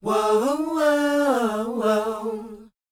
WHOA A C U.wav